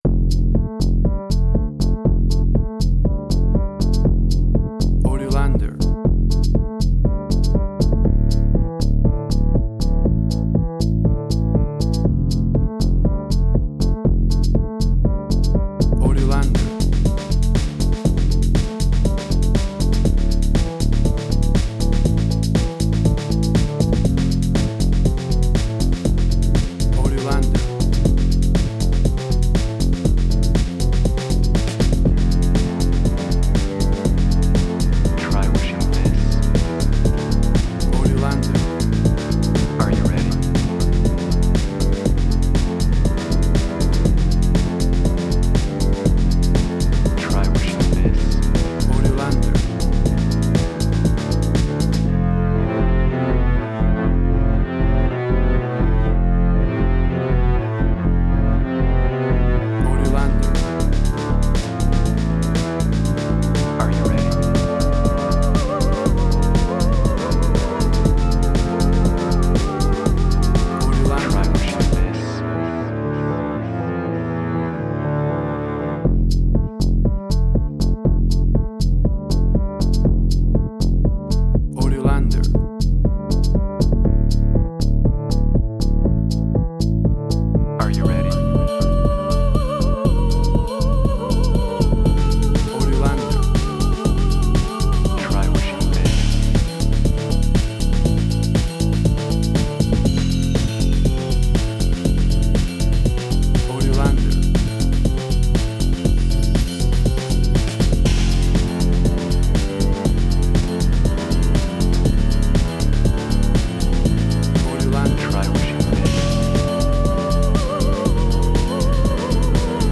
emotional music
WAV Sample Rate: 16-Bit stereo, 44.1 kHz
Tempo (BPM): 120